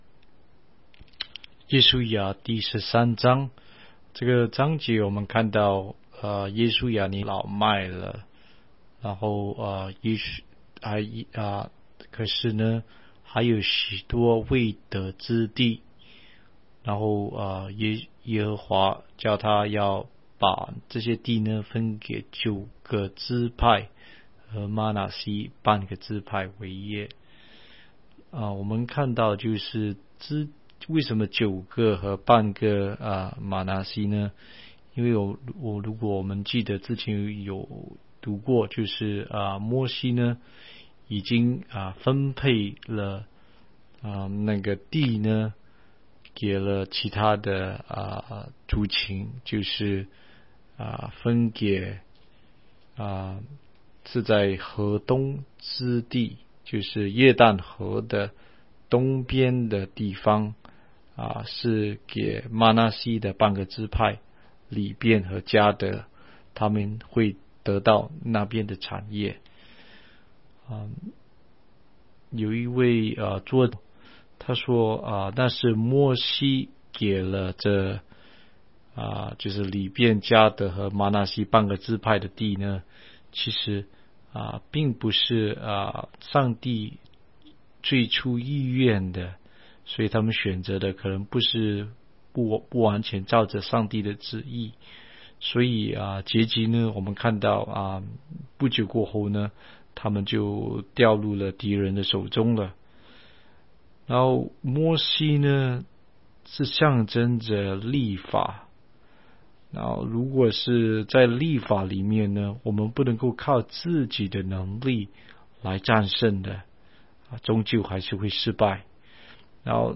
16街讲道录音 - 每日读经-《约书亚记》13章